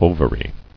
[o·va·ry]